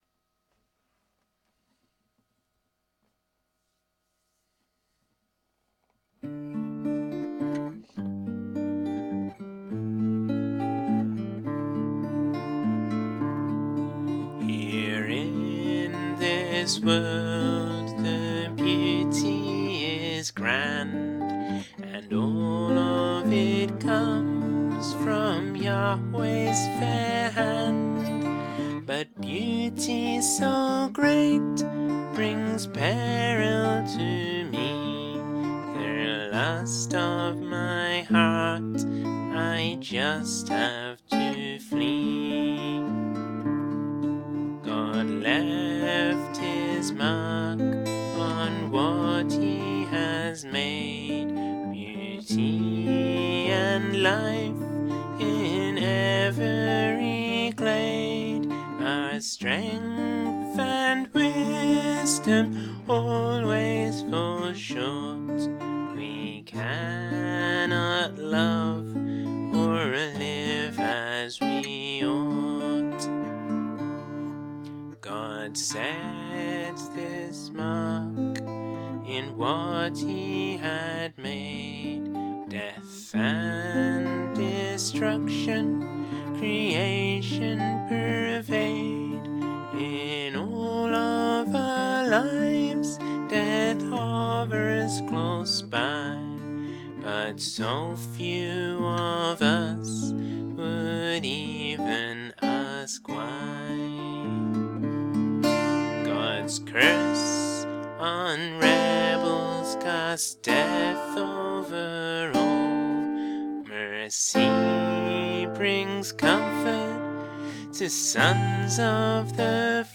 with just voice and guitar: